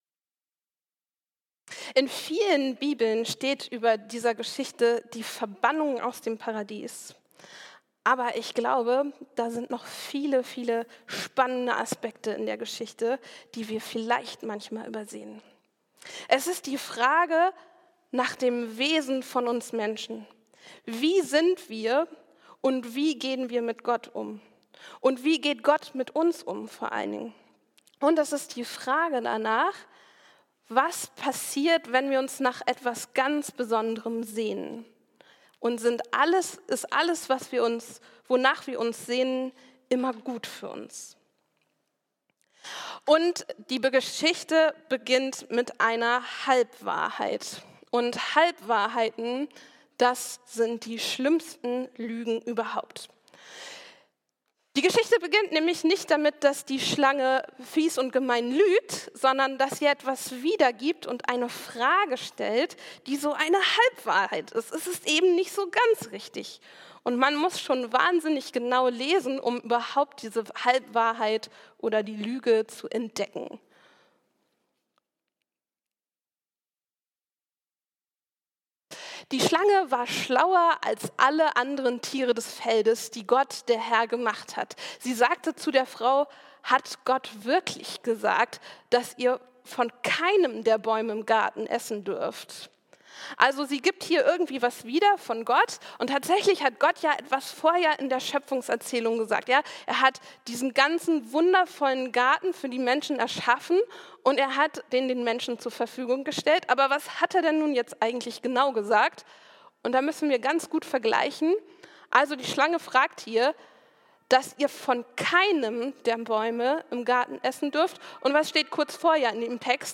Verbannung aus dem Paradies ~ Christuskirche Uetersen Predigt-Podcast Podcast